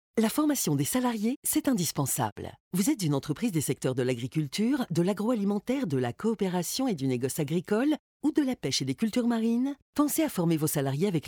SPOT RADIO CATALOGUE OFFRE REGIONALE
SPOT-RADIO-OCAPIAT-catalogue-OR.mp3